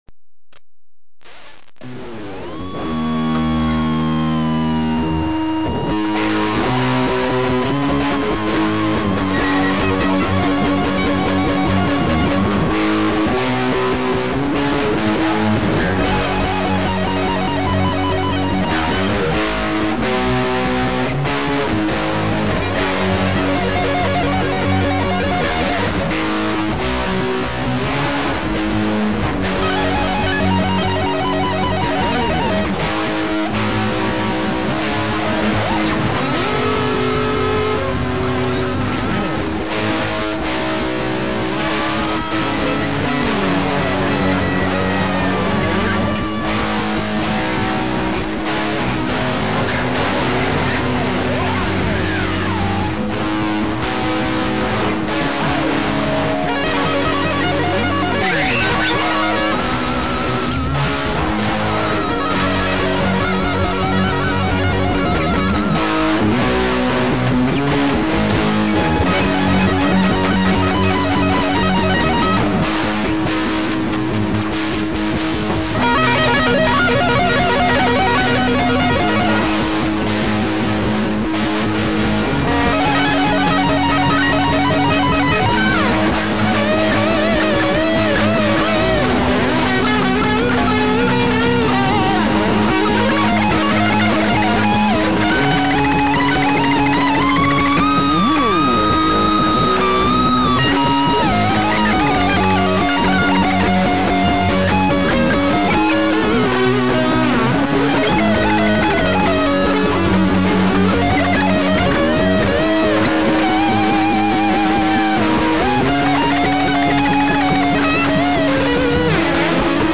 はっきり言って雑音です。 (MP3､完成度は無茶苦茶低いです)